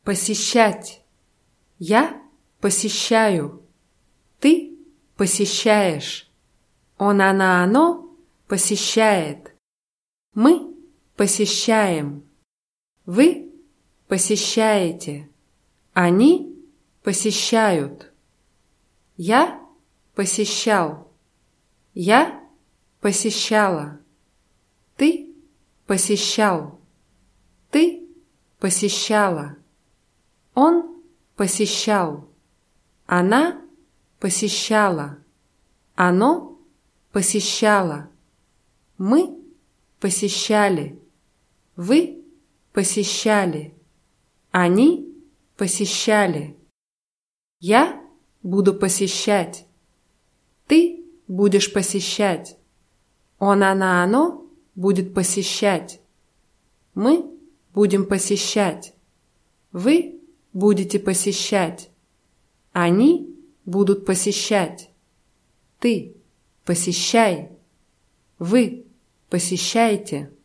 посещать [paßʲischtschátʲ]